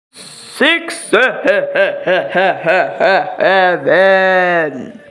Evil Laugh 67 Sound Button - Free Download & Play